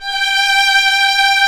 55m-orc13-G4.wav